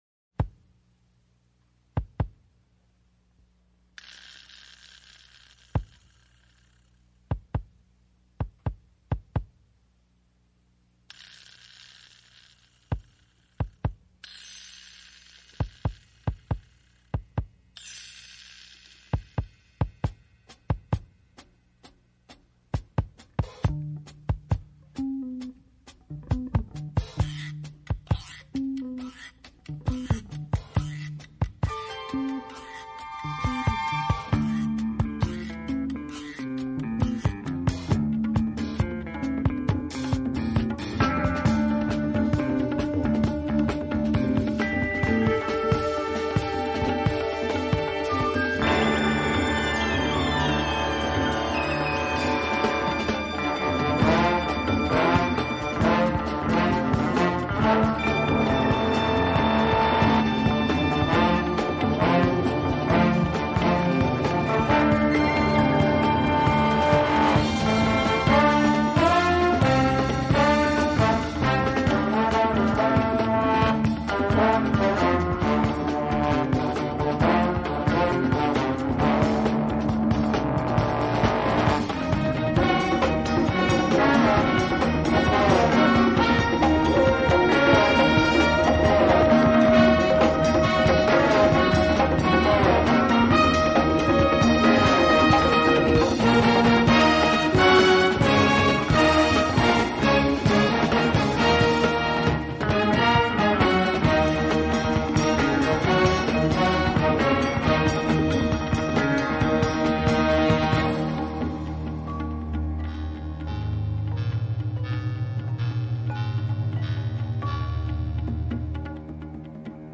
l’orientalisme musclé